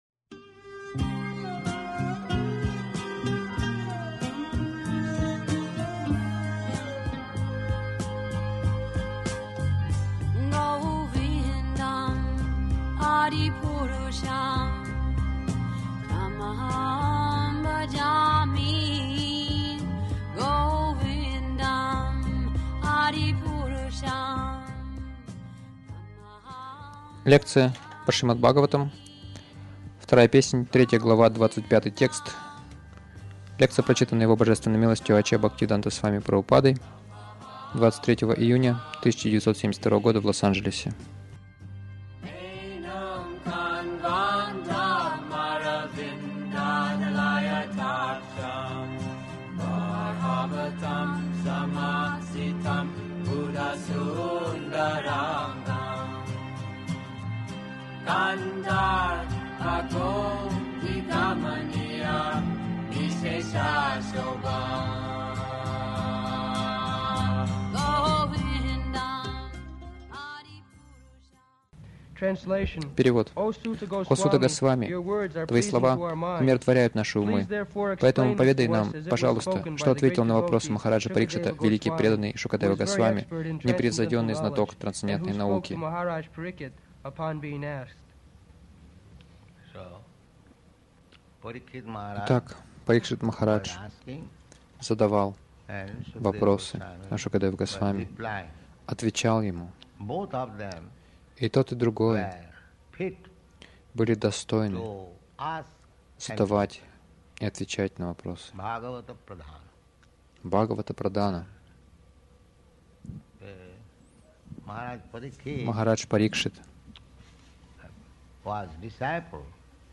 Милость Прабхупады Аудиолекции и книги 23.06.1972 Шримад Бхагаватам | Лос-Анджелес ШБ 02.03.25 — Духовное осознание через звук Загрузка...